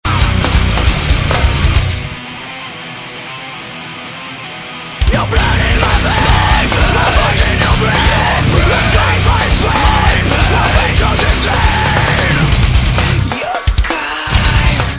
neo métal